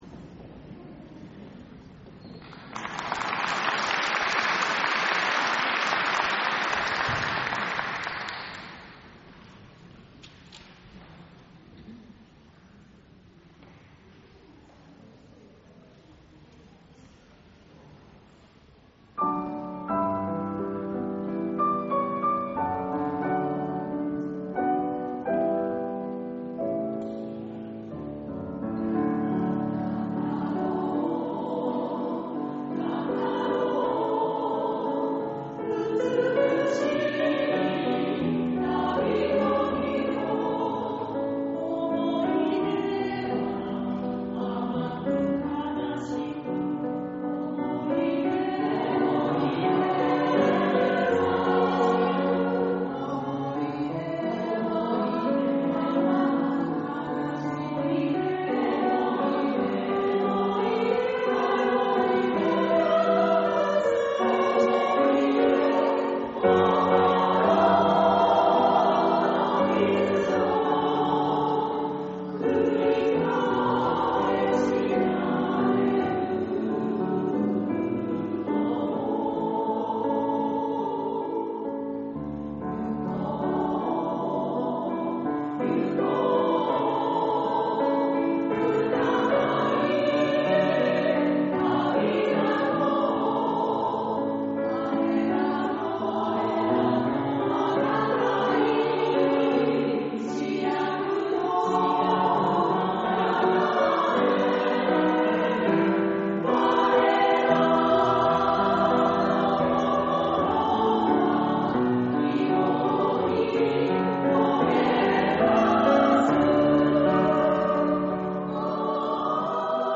第６０回台東区合唱祭が、平成２７年１１月１５日（日）に東京藝術大学奏楽堂で開催されました。
女声合唱のための組曲「旅」より
おやすみなさい（女声３部合唱）　宮田滋子作詞　早川史郎作曲